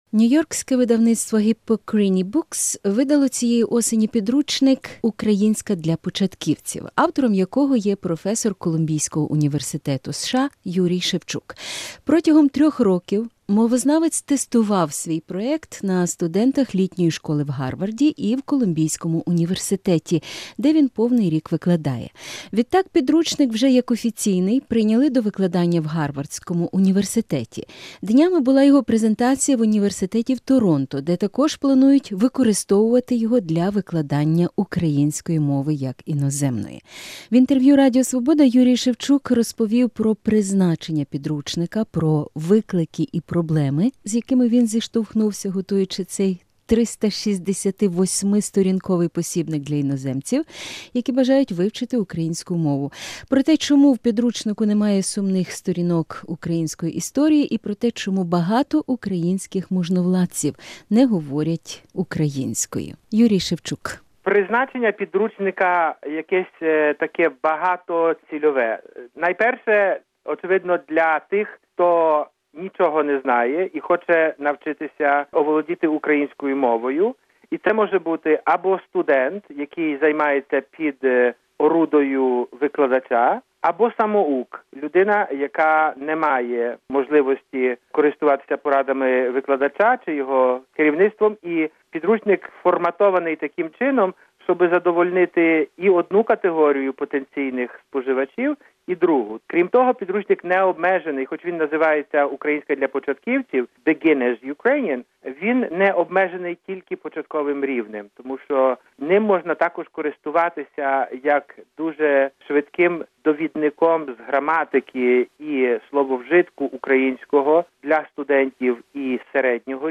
Інтерв’ю з Юрієм Шевчуком